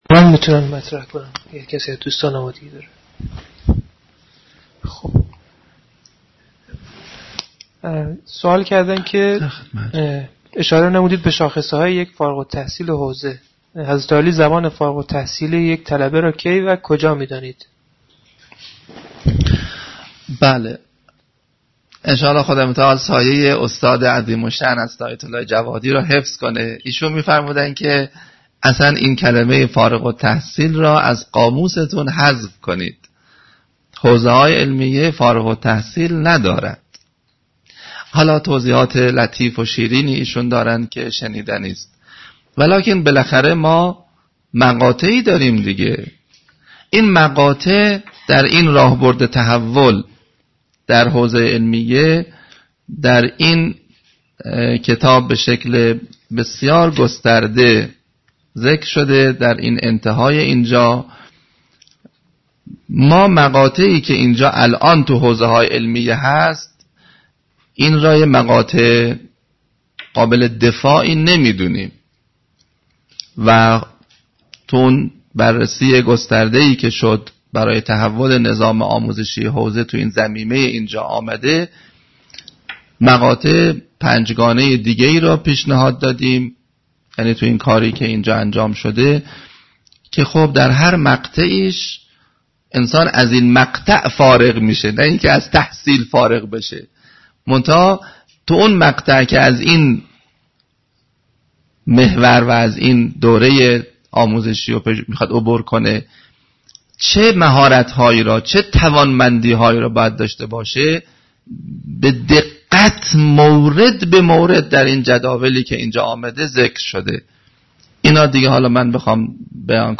پنجمین نشست همایش تبیین نسبت حوزه و انقلاب
پرسش-و-پاسخ.mp3